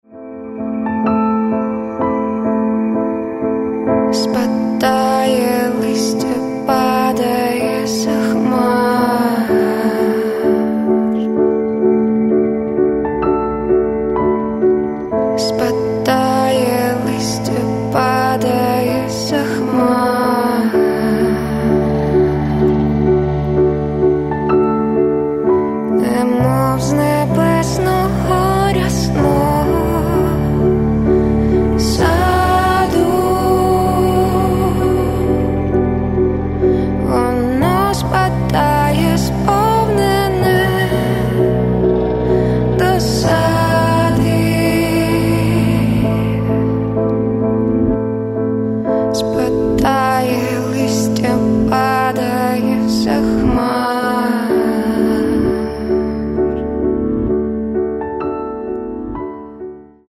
Rock & Alternative